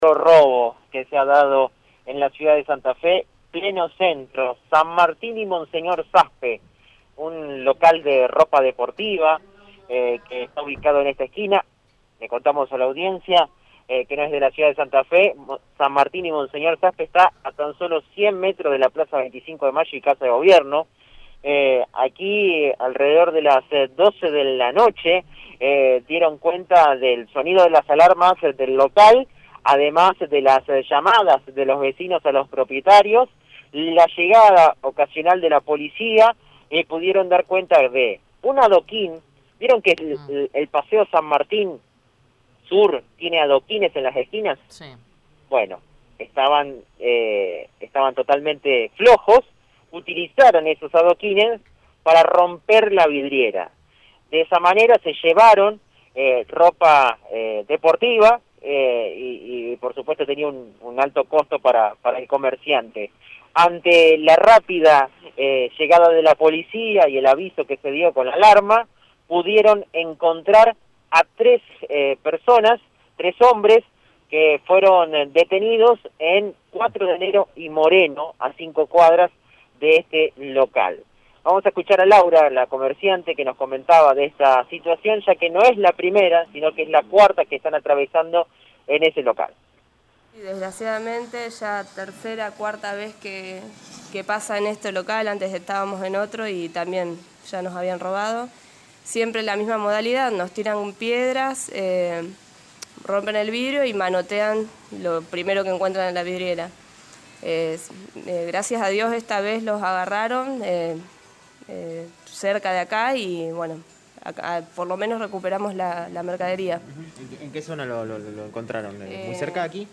La mujer indicó que esta vez lograron recuperar la mercadería ya que se detuvo a los delincuentes.